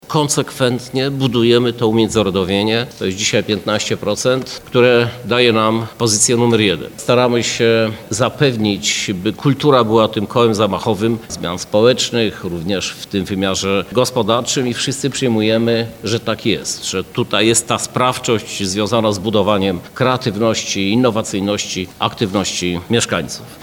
• mówi Prezydent Lublina Krzysztof Żuk.